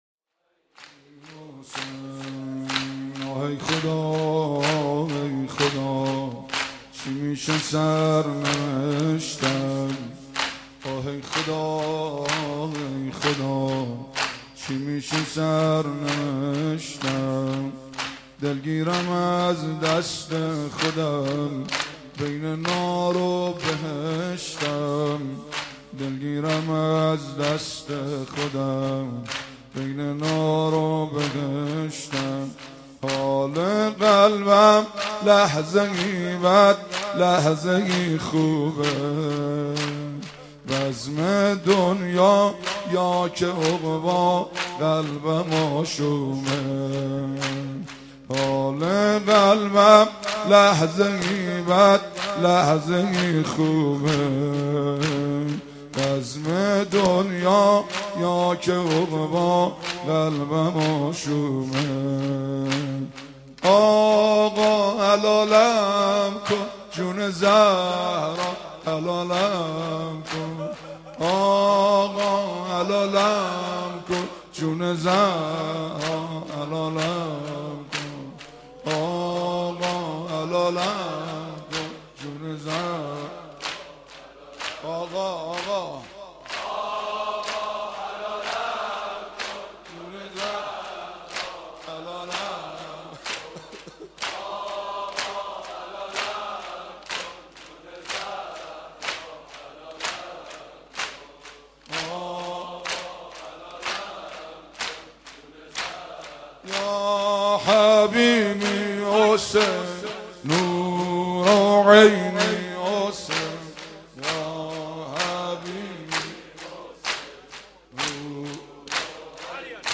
شب 4 محرم 1392
• سینه زنی حضرت حر، آقا حلالم کن
• مداحی